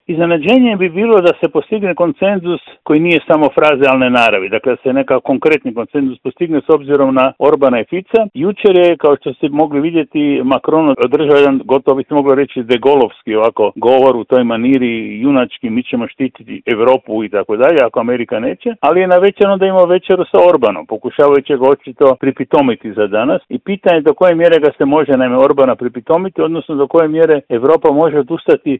Razgovor je za Media servis komentirao politički analitičar Žarko Puhovski: